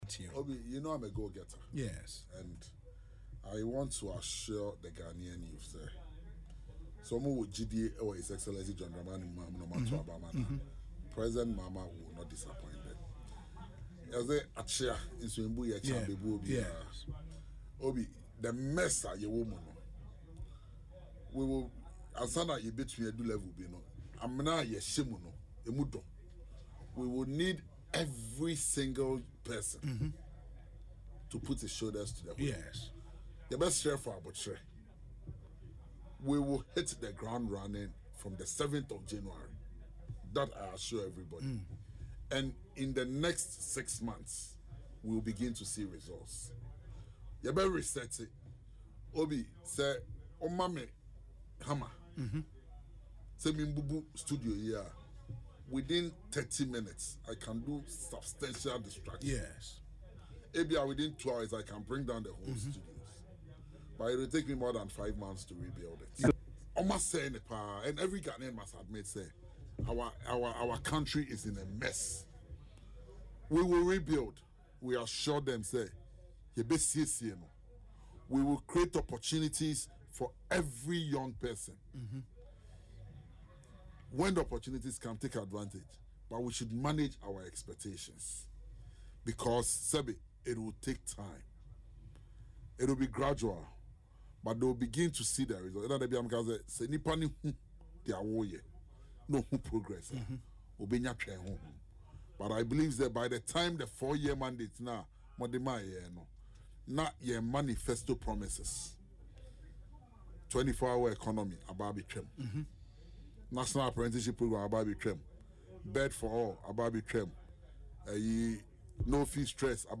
Speaking on Asempa FM Ekosii Sen, George Opare Addo assured the President-elect, John Mahama will not disappoint, particularly the Ghanaian youth.